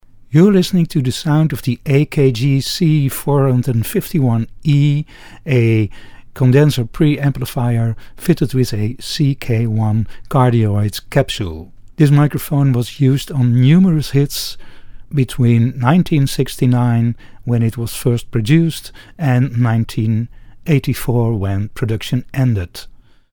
Keep in mind that there is not a single microphone that suits every sound source, and these vintage AKGs may seem too bright, if heard on their own, but they work remarkably well in the mix.
Below: sound, C451E with VR1 extension and CK1, Parts and the fragile thread of the C451 preamplifier
AKG C451-CK1 sound UK.mp3